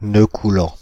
Ääntäminen
Ääntäminen France (Paris): IPA: /nø ku.lɑ̃/ Haettu sana löytyi näillä lähdekielillä: ranska Käännös Substantiivit 1. клуп Suku: m .